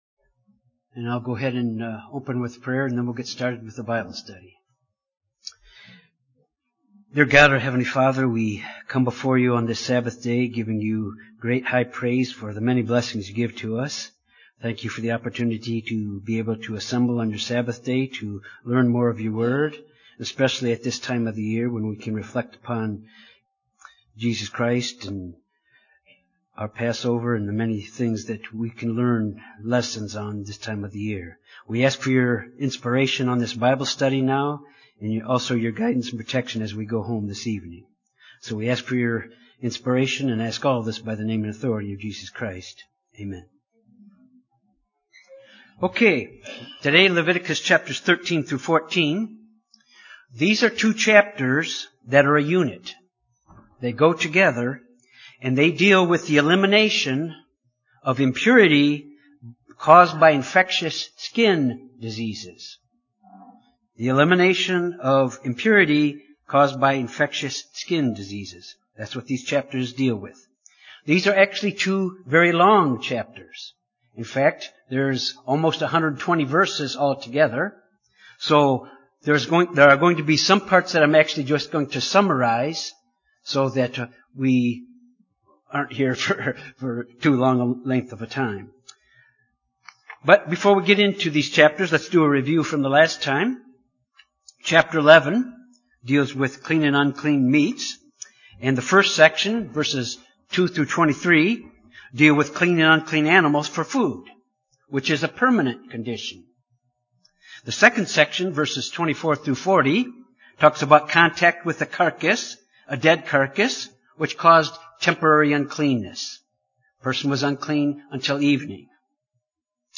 This Bible Study deals with the elimination of impurities caused by infectious skin diseases. By using the principle of quarantine, God teaches how to keep infectious persons from exposing others to any contagious or deadly disease.
Given in Little Rock, AR